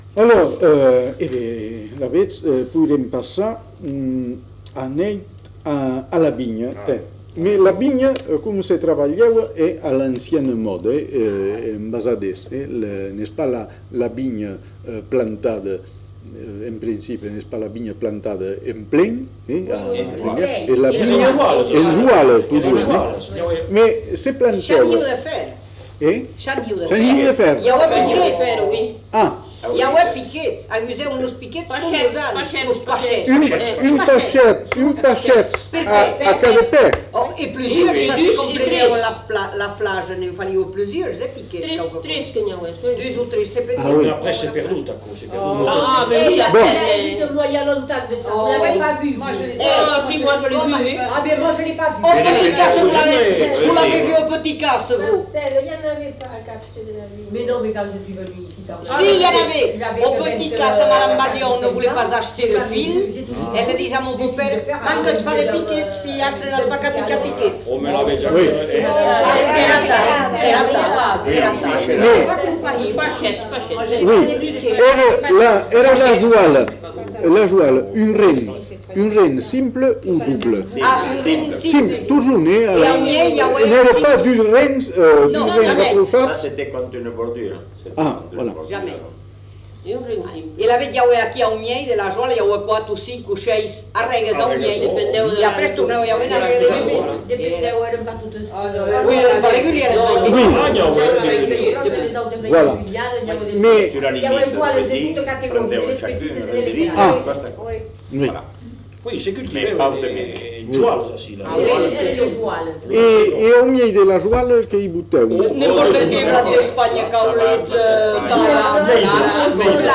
Genre : témoignage thématique
Plusieurs informateurs ne sont pas identifiés.